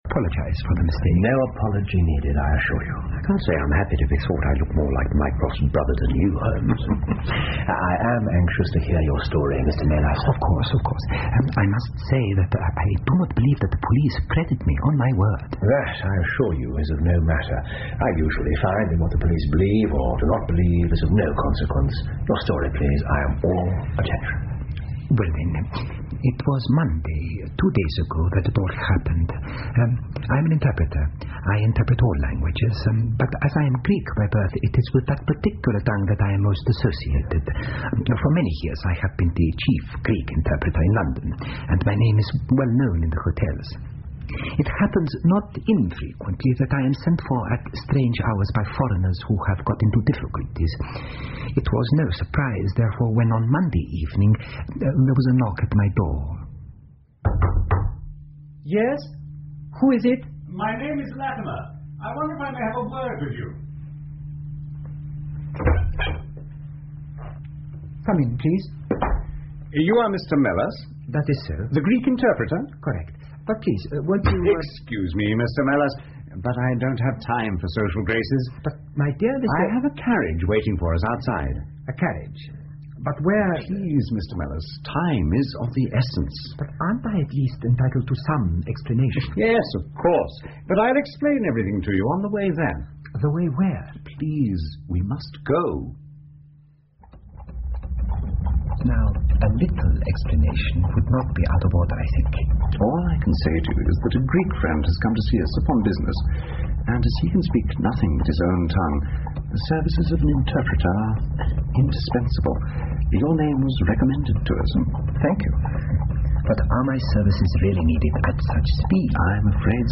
福尔摩斯广播剧 The Greek Interpreter 3 听力文件下载—在线英语听力室